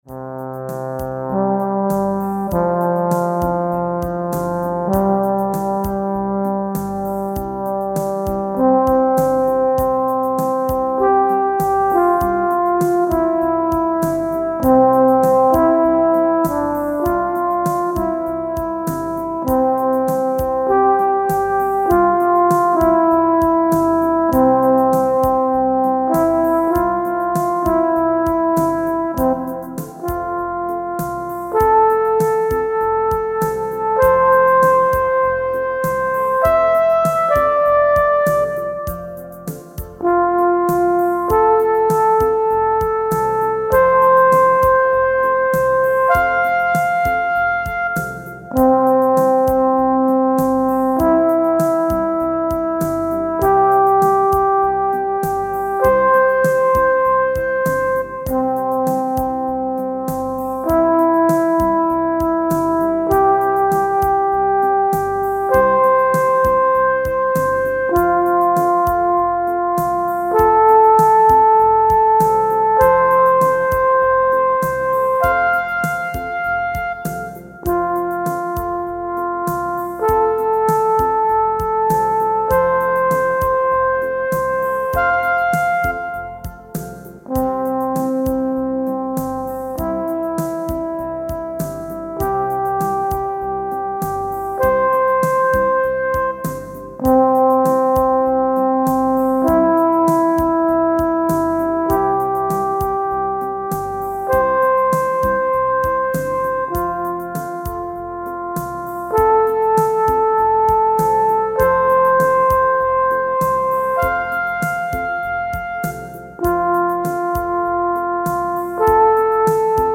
Horn sounds pending with easy beats.
Tempo: 60 bpm / Date: 31.05.2017
Jazz /Creative Commons License 4.0 / noncommercial use free